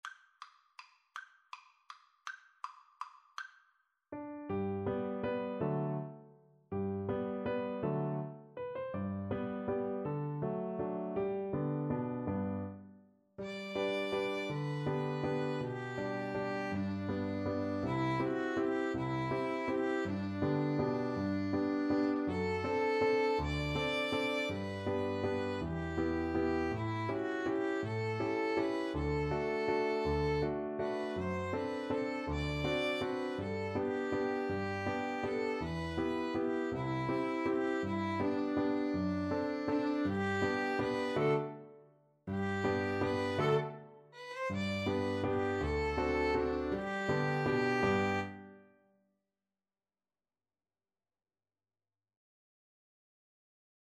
3/4 (View more 3/4 Music)
One in a bar . = c.54
Pop (View more Pop Piano Trio Music)